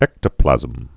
(ĕktə-plăzəm)